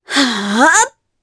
Reina-Vox_Casting3_jp.wav